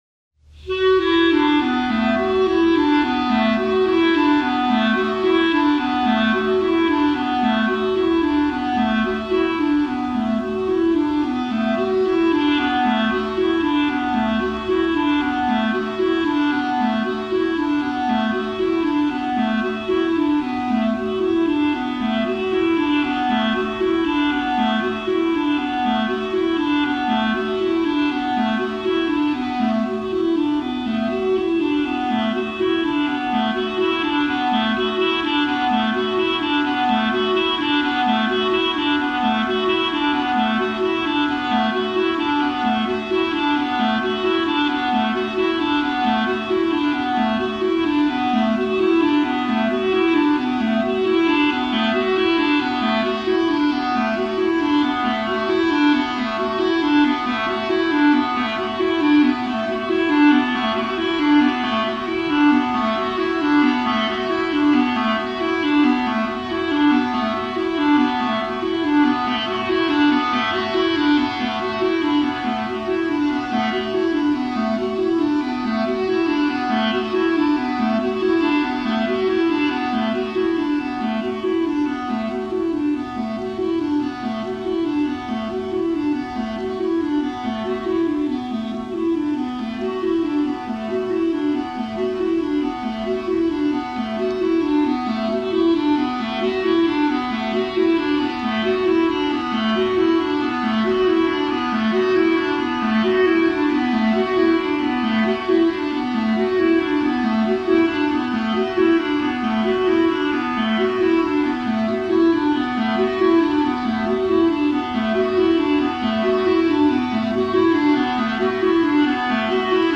clarinet solo